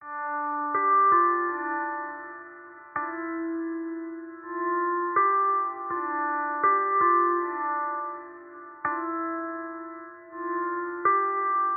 Tag: 163 bpm Trap Loops Bells Loops 1.98 MB wav Key : D